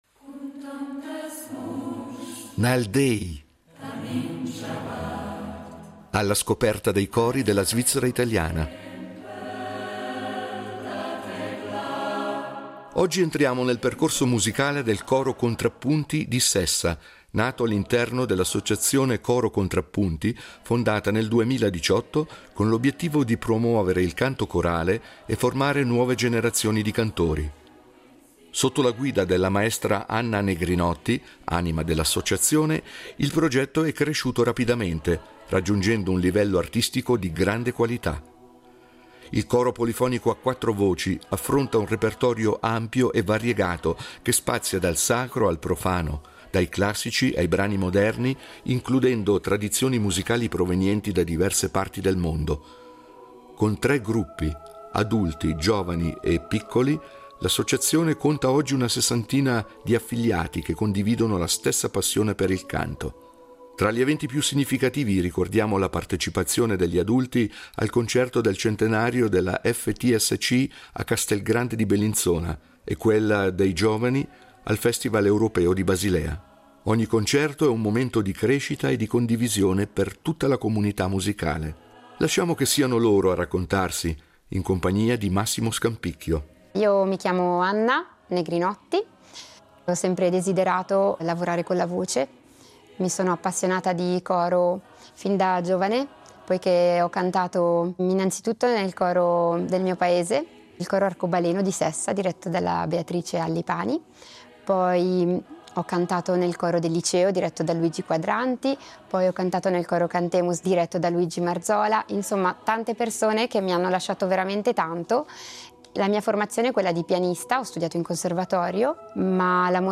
Il coro polifonico a quattro voci affronta un repertorio ampio e variegato, che spazia dal sacro al profano, dai classici ai brani moderni, includendo tradizioni musicali provenienti da diverse parti del mondo. Con tre gruppi – adulti, giovani e piccoli – l’associazione conta oggi una sessantina di affiliati che condividono la stessa passione per il canto.